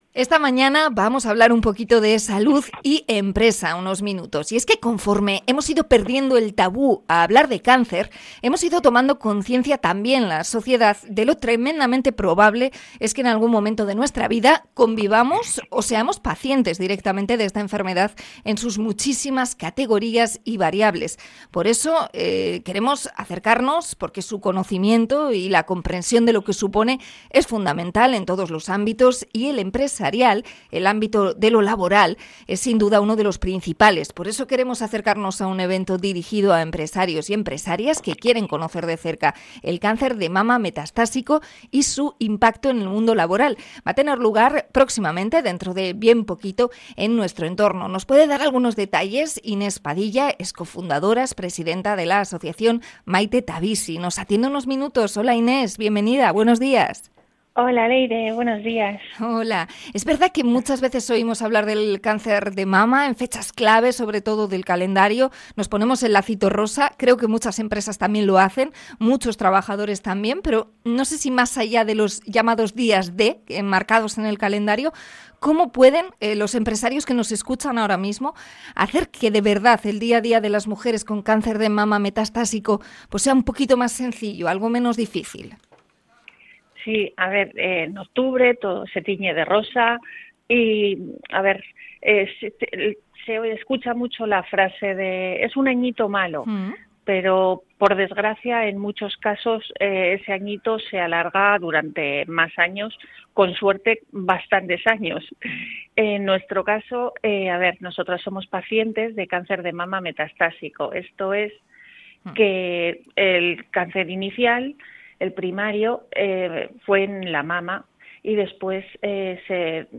Entrevista a la asociación Maite ta Bizi por el evento "Más allá del rosa"